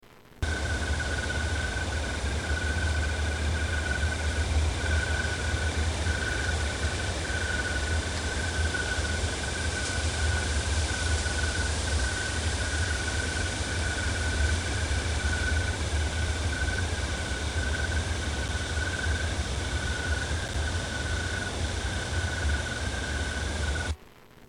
Crickets Sound on Cape Cod